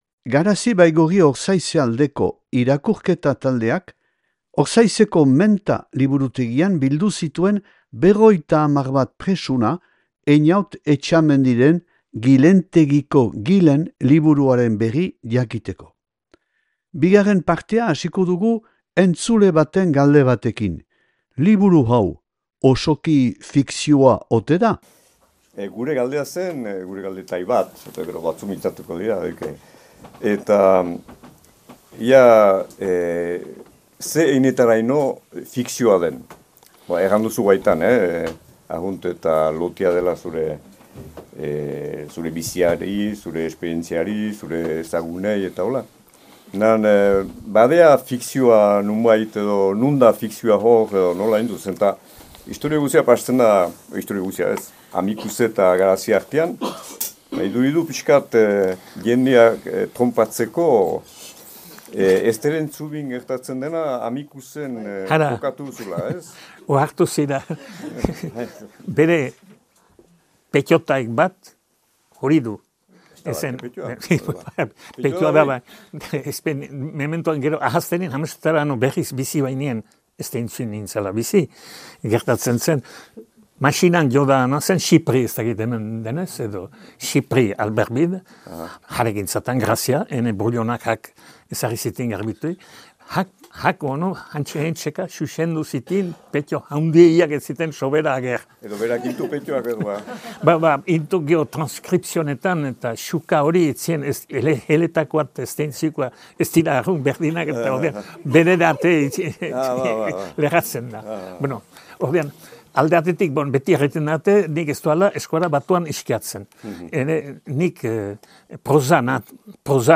Aldude Baigorri Ortzaizeko irakurle taldeak antolaturik Ortzaizeko Menta liburutegian 2024. azaroaren 14an.